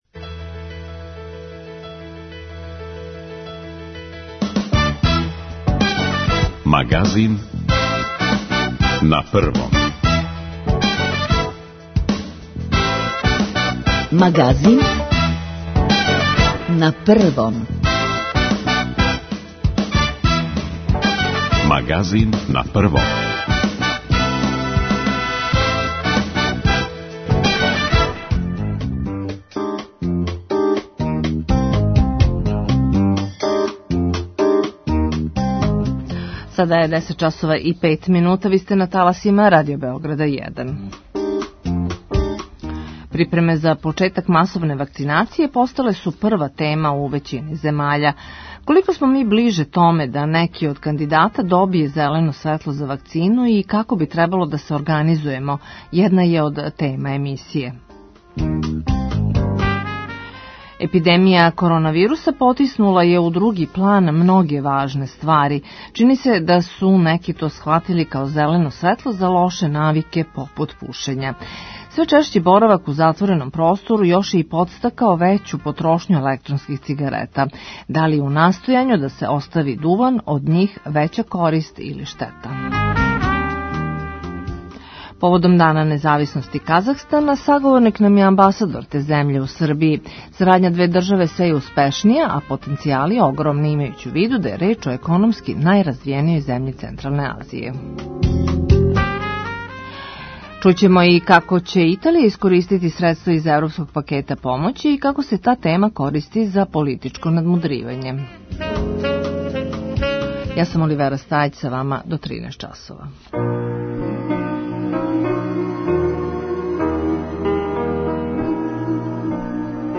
Поводом Дана независности Казахстана, саговорник нам је амбасадор те земље у Србији, Габит Сиздикбеков.